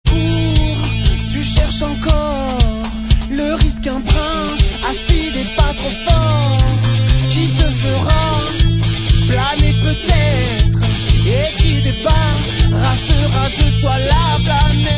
funk rock